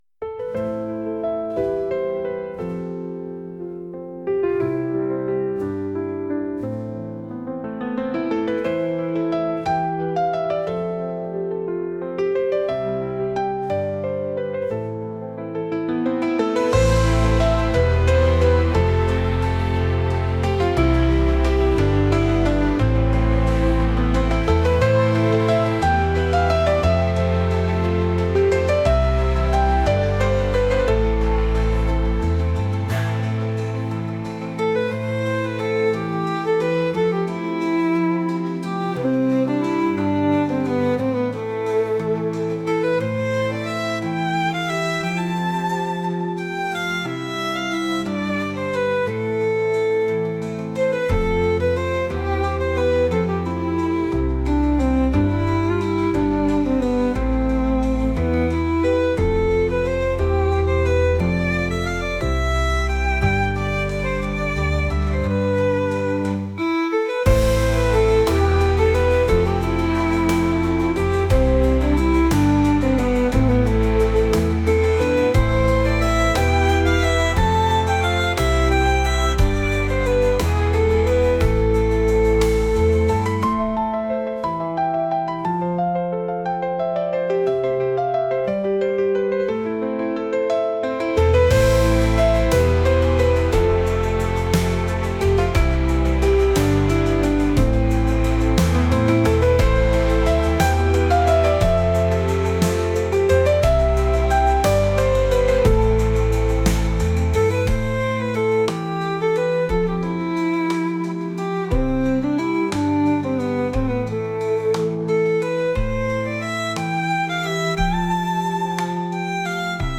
山の新緑に目を奪われるようなケルト音楽です。